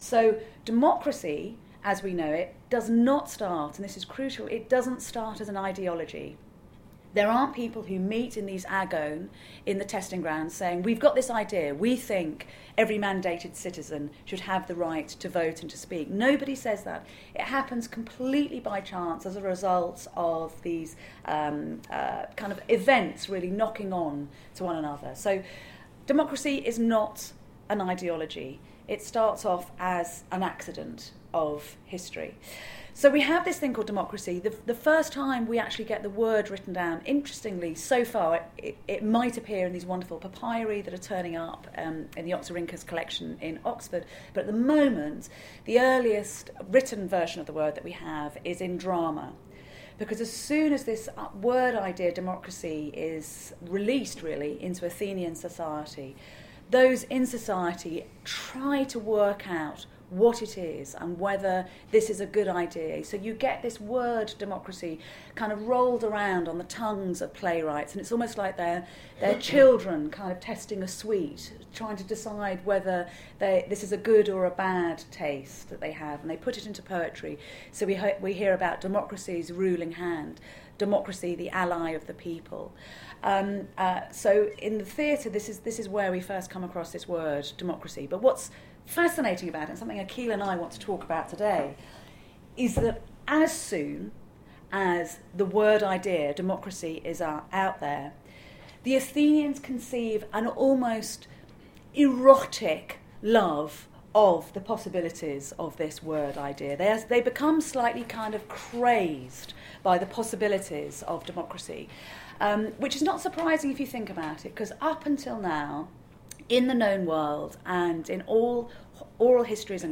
How democracy spreads in Athens. Historian, author & broadcaster Bettany Hughes discusses the origins of democracy at Middlesex University.